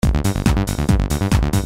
Qui potrete trovare files in formato .rbs e .wav da usare in modo loop, per poterli edittare, trasformare, oppure elaborare in sequenza con programmi appropriati, che sicuramente, gli appassionati a questo tipo di lavoro conoscono molto bene.
Sequence mp3